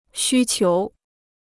需求 (xū qiú): requirement; to require.